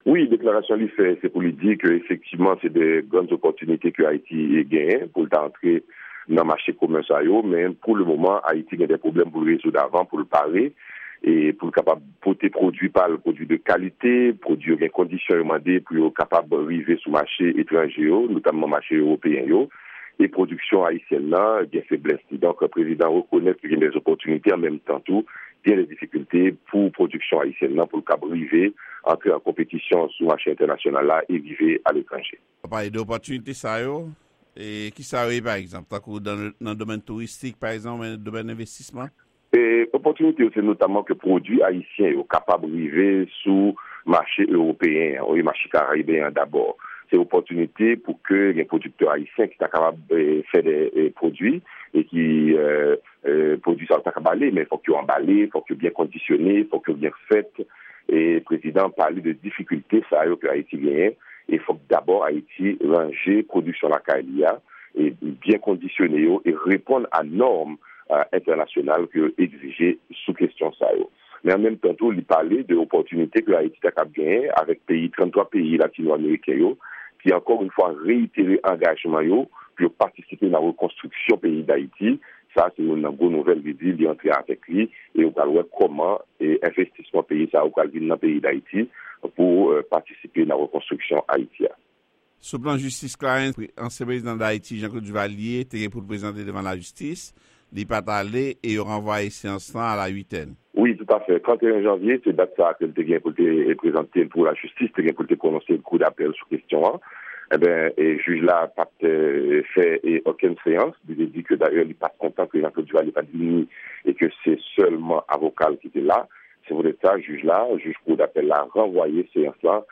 Entèvyou